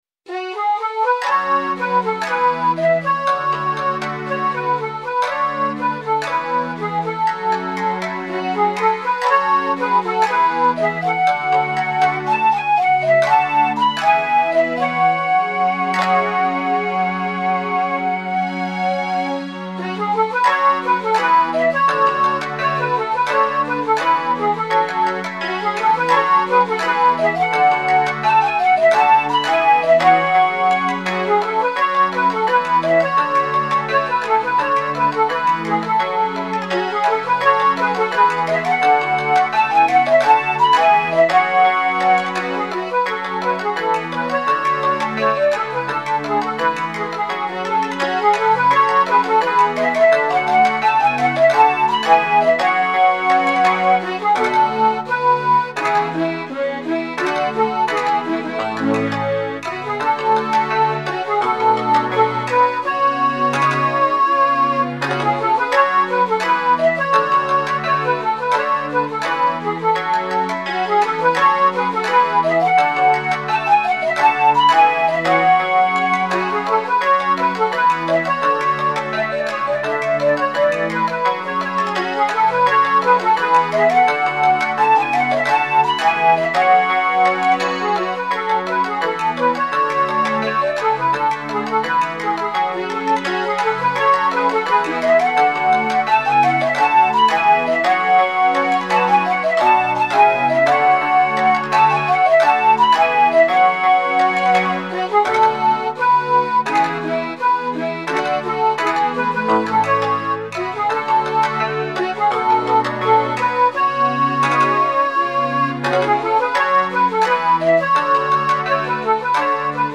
Japanese Music Style
ゆっくりめのイントロからテンポが上がります。お喋りで勇敢な雰囲気。 尺八メイン、サブに三味線による和風アレンジ。